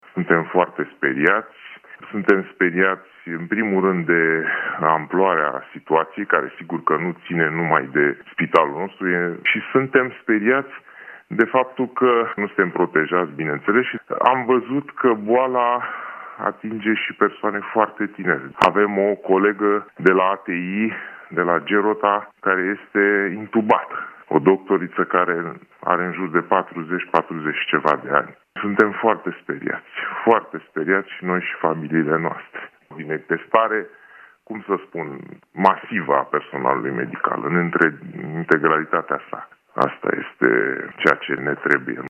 29mar-12-medic-Sfantul-Ioan-suntem-speriati-DISTORSIONAT.mp3